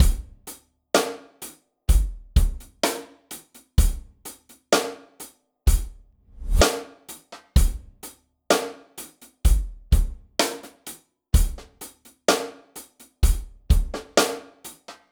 Track 10 - Drum Break 03.wav